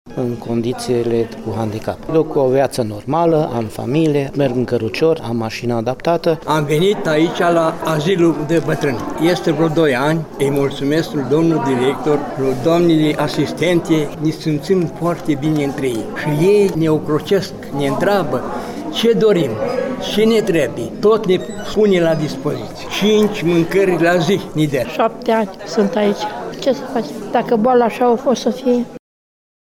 Cum se identifică ei în societate cu „poziţia” de persoană cu dizabilităţi, am aflat de la unii dintre aceştia: